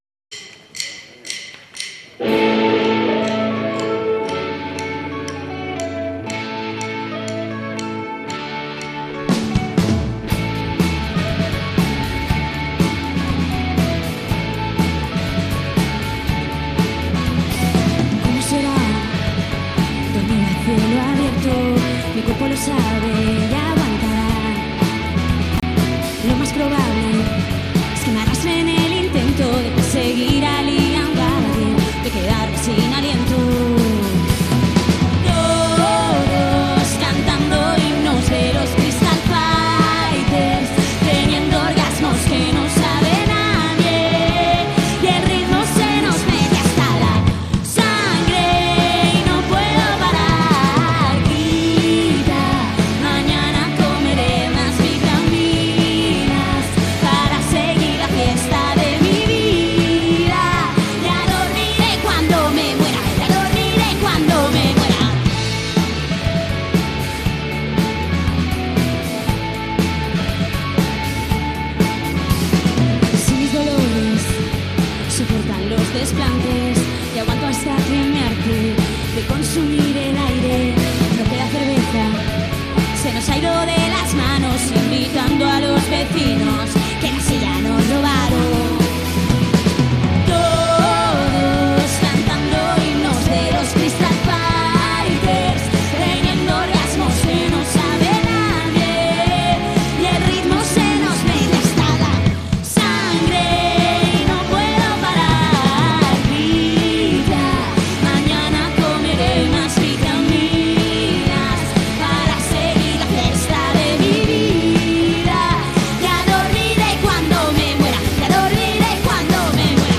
recorded with social distance
rhythm guitar and lead vocals
lead guitar and second voices
bass
drums